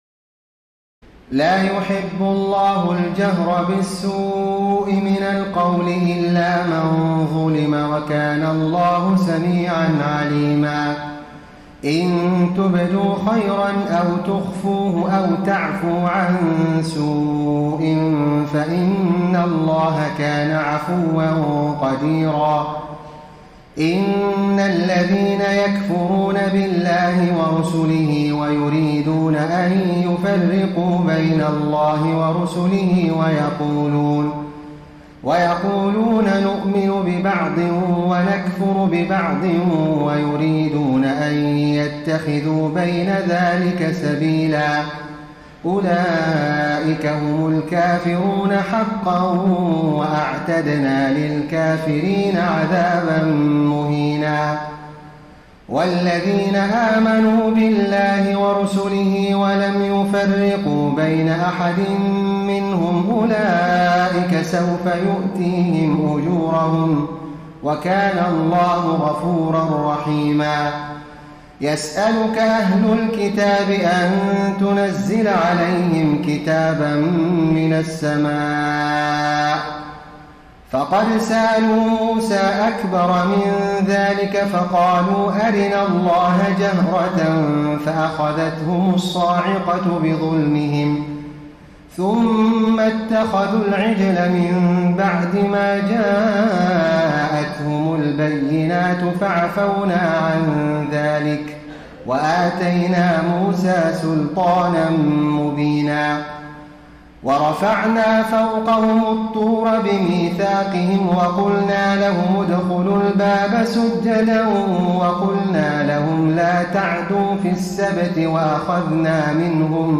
تراويح الليلة السادسة رمضان 1433هـ من سورتي النساء (148-176) و المائدة (1-26) Taraweeh 6 st night Ramadan 1433H from Surah An-Nisaa and AlMa'idah > تراويح الحرم النبوي عام 1433 🕌 > التراويح - تلاوات الحرمين